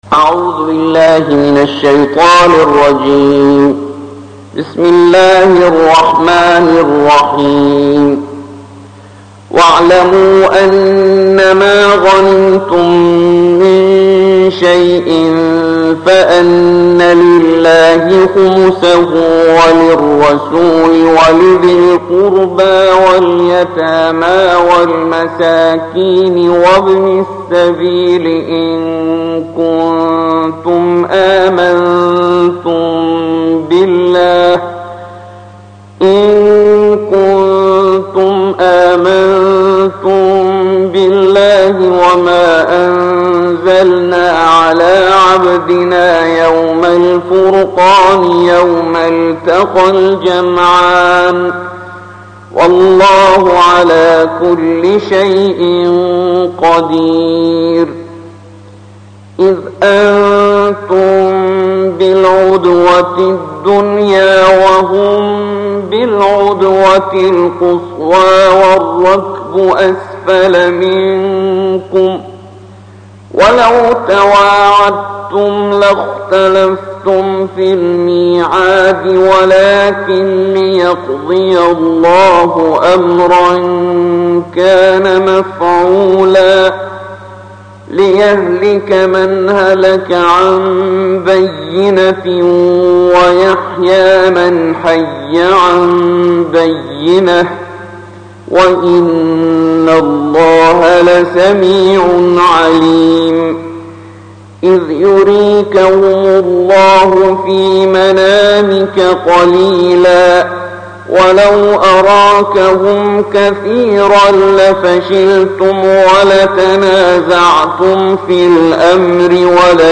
الجزء العاشر / القارئ